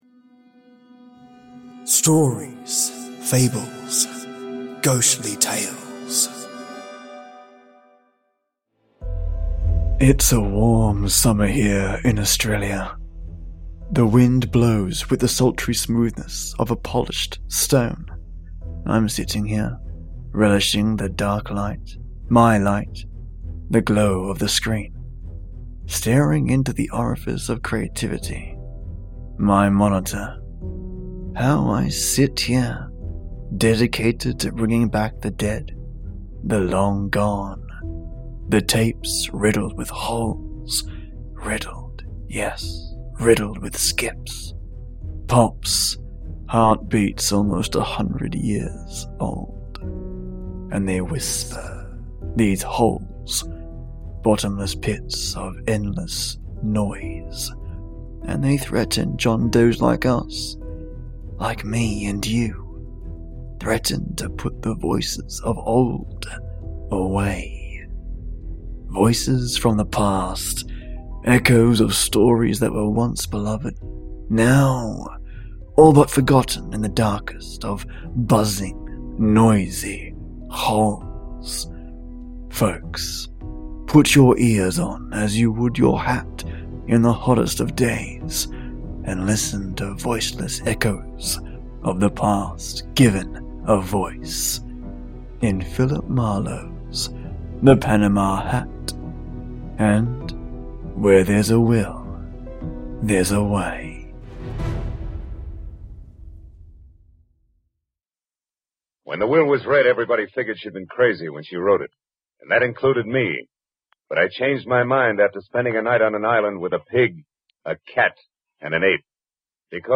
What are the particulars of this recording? Riddled…yeah…riddled with skips…pops…heart beats almost a hundred years old...and they whisper, these holes – bottomless pits of endless noise – and they threaten John Doe’s luck us.